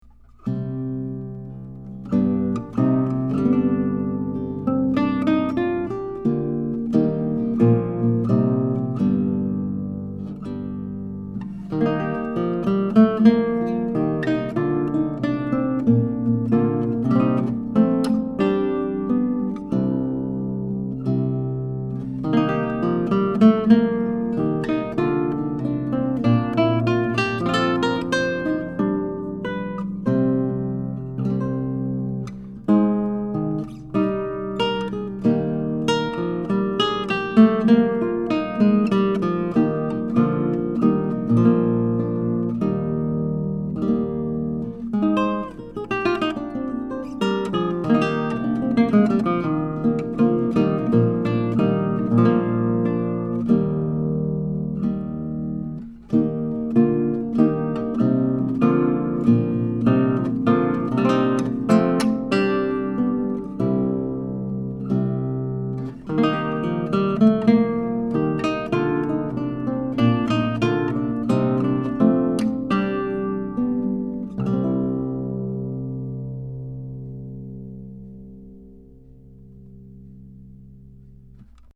11-String Guitar
This guitar has a AAA-grade solid Cedar top, laminated East Indian Rosewood back & Sides, ebony fretboard, and creates a beautifully-balanced sound with nice trebles, deep basses, and excellent resonance and sympathetic sustain.
I have the guitar tuned in Romantic tuning in to G, a standard 11-string tuning where 1-6 are tuned up a minor third, and 7-11 descend step-wise: 7=D, 8=C, 9=B, 10 =A, 11=G. It can also be tuned in Dm tuning for Baroque Dm lute music.
Here are 12 quick, 1-take MP3s of this guitar, tracked using a vintage Neumann U87 mic, into a TAB-Funkenwerk V78M tube preamp using a Sony PCM D1 flash recorder. This is straight, pure signal with no additional reverb, EQ or any other effects.